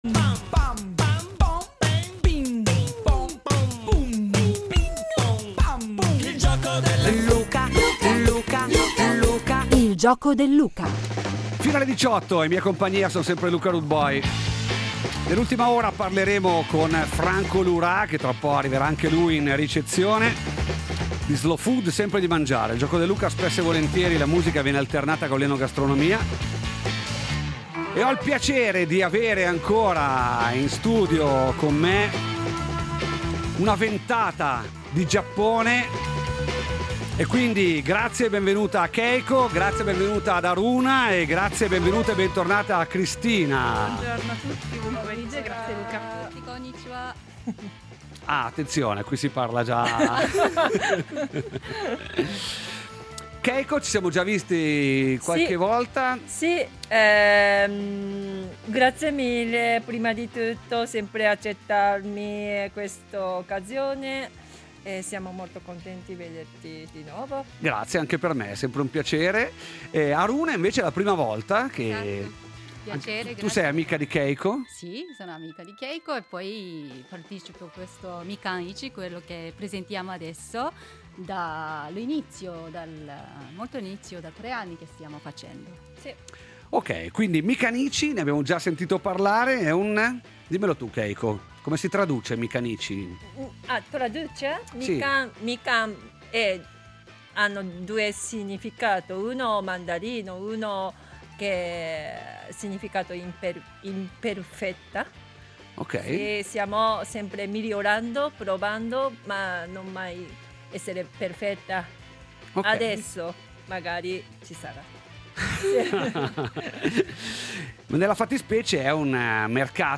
Si va in Giappone! In studio